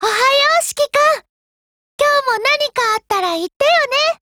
贡献 ） 协议：Copyright，其他分类： 分类:语音 、 分类:少女前线:UMP9 您不可以覆盖此文件。
UMP9_0_HELLO_JP.wav